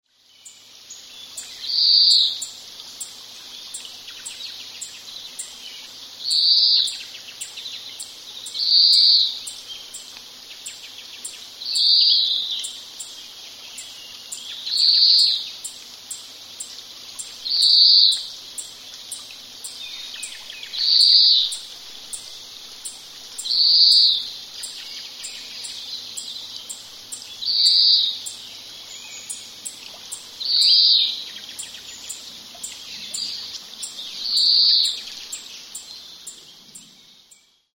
kawarahiwa_s1.mp3